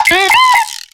Cri de Keunotor dans Pokémon X et Y.